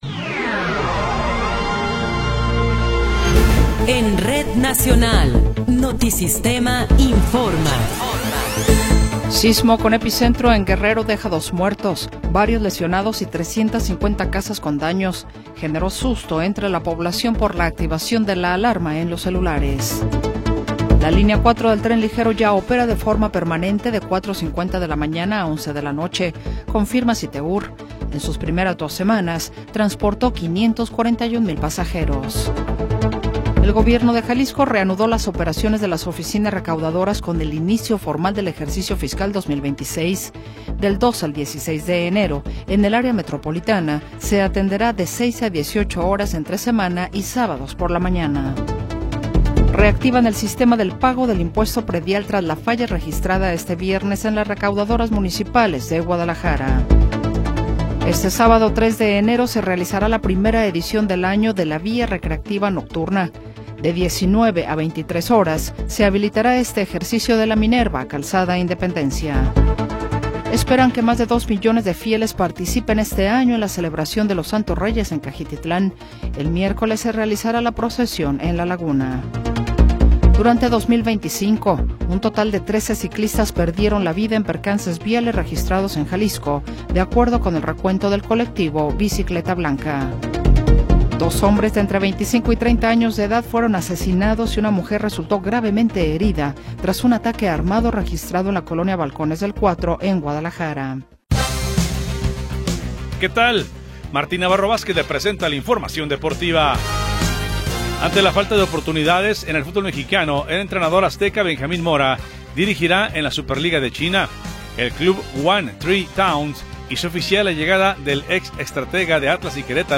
Noticiero 21 hrs. – 2 de Enero de 2026